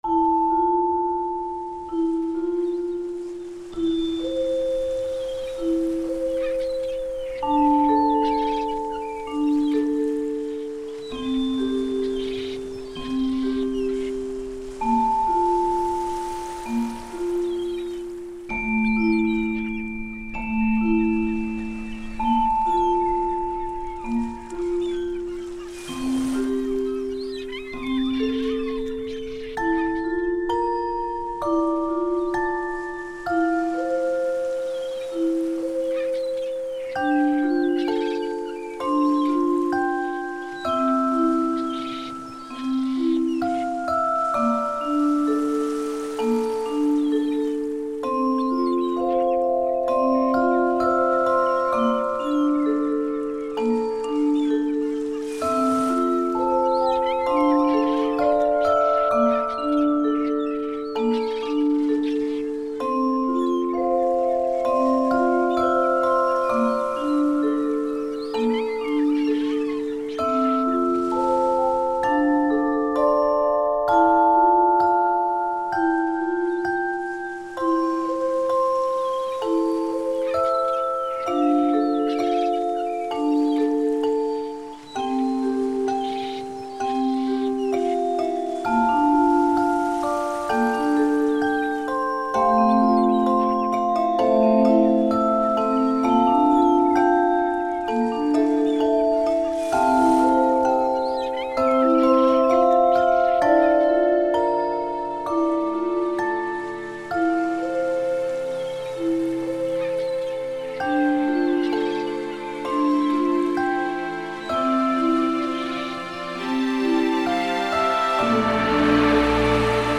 ewql orchestra strings chimes percussion choir metals kambanite church bells music box raum reverb pensive thoughtful sad memories lament
the waves feel almost muted, like static.